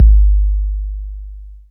808 Bass.wav